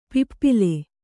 ♪ pippile